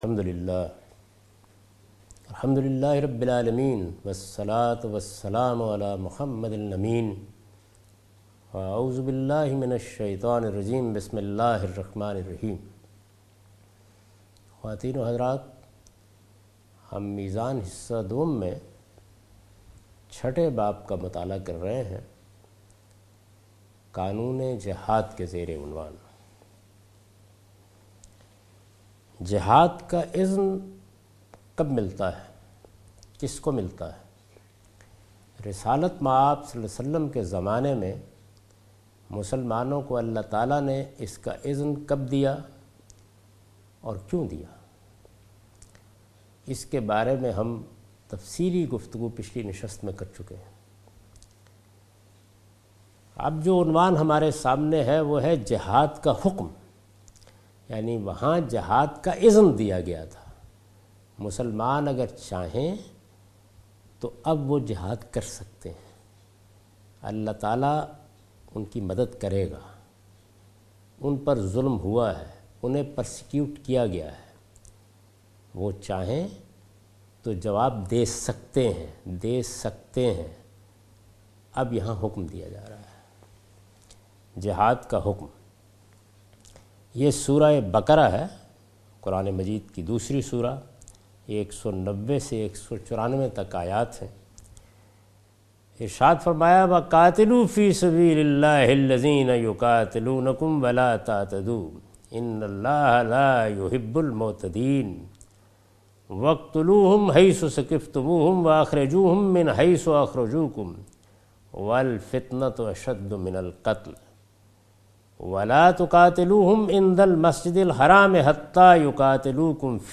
A comprehensive course taught by Javed Ahmed Ghamidi on his book Meezan.
The The Directive of Jihad is discussed in this lecture. Questions regarding the directive of Jihad that when and where this directive was issued are also discussed.